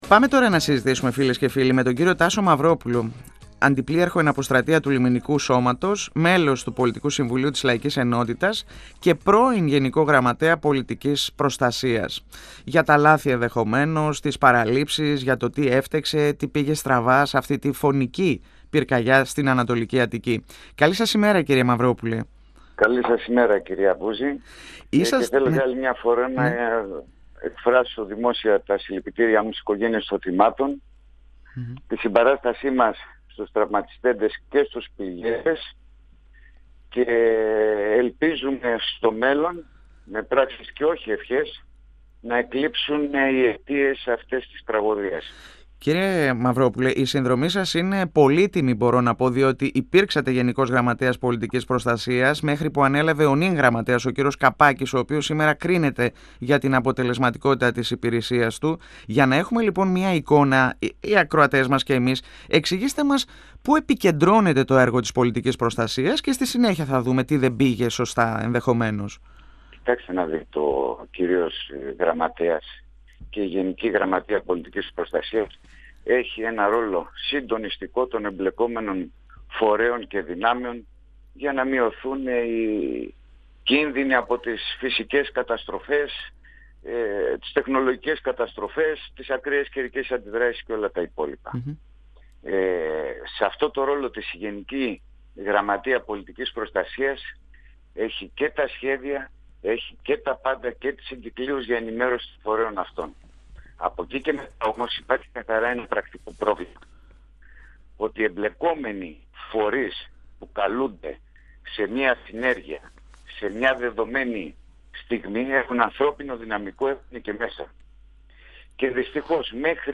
Ο πρώην Γ.Γ. Πολιτικής Προστασίας Τάσος Μαυρόπουλος στον 102 fm της ΕΡΤ3
Συνέντευξη